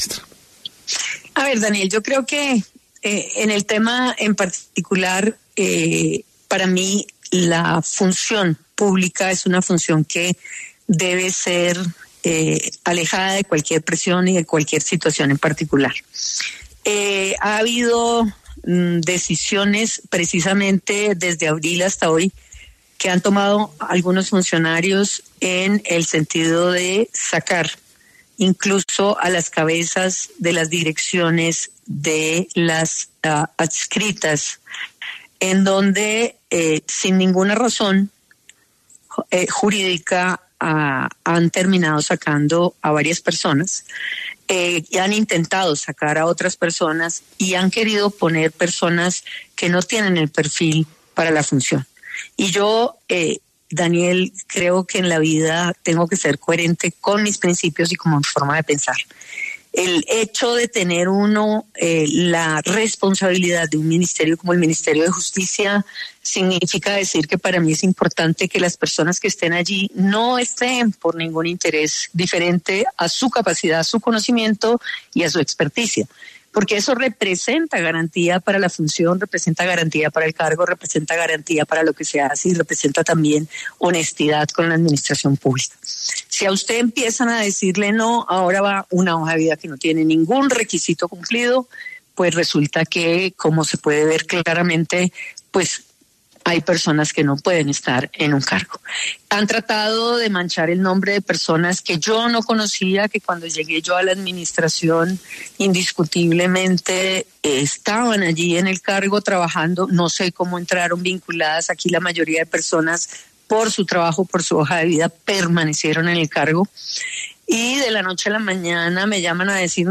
En diálogo con El Reporte Coronell, la ministra de Justicia, Ángela María Buitrago, explicó el porqué de su renuncia.